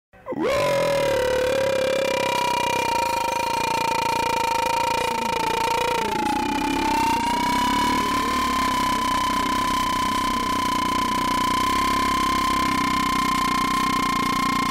Rawr Krakatau Meme Sound sound effects free download